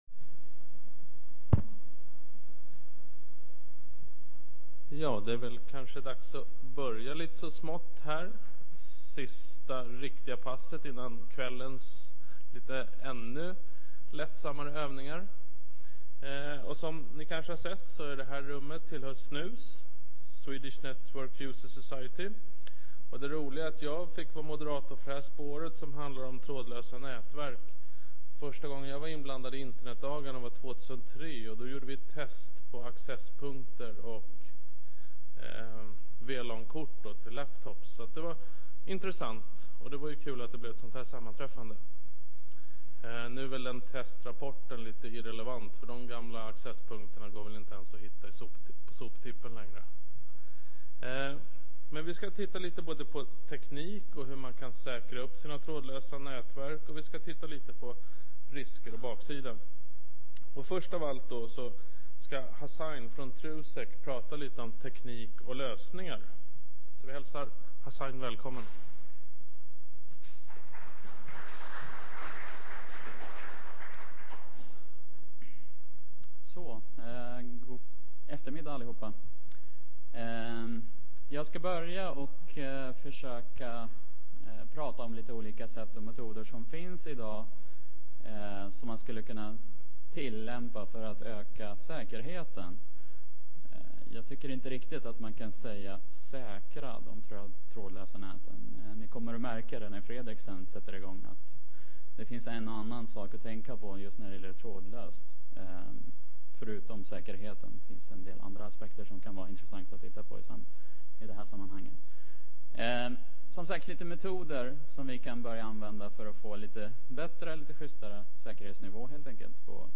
I det h�r seminariet tar vi upp s�kerhetsproblemet och hur det b�r hanteras.